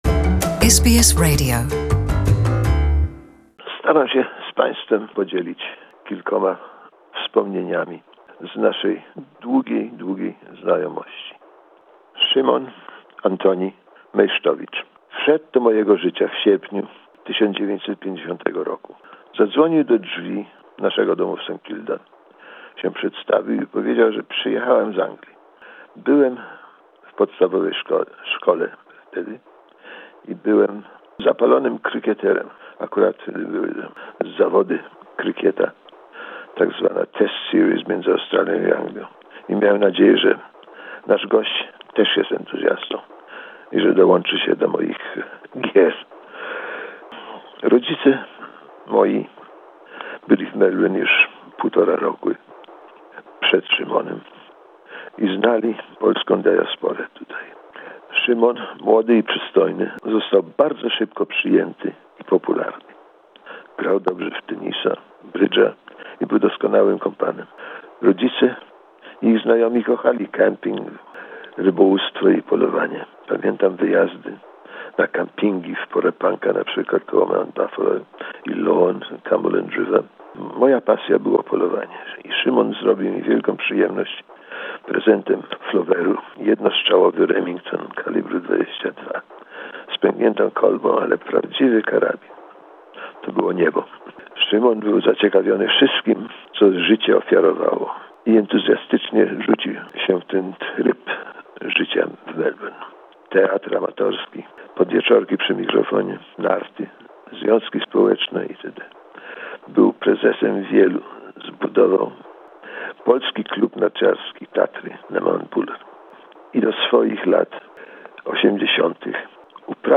The Honorary Consul General of the Republic of Poland, George Luk-Kozika, recalls a long-time friend